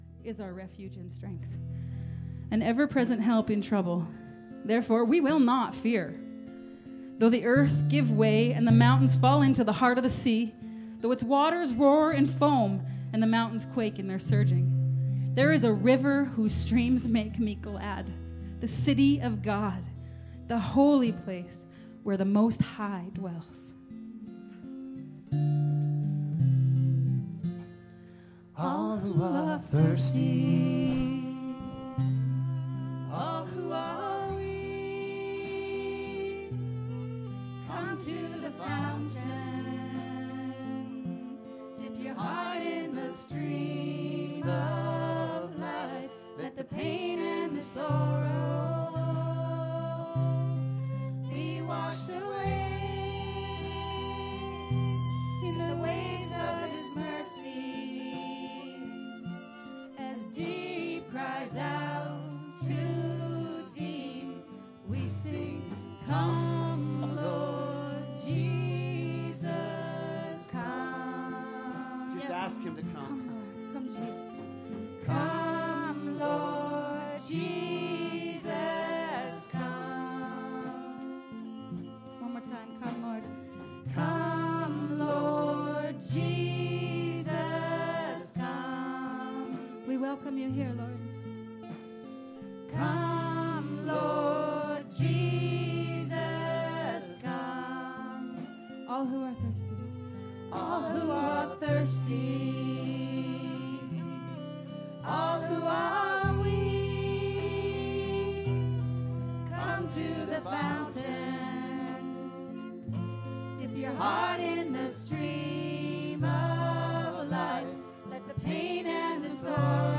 Sunday May 27 – Sermon and audio | Potters Hands Ministries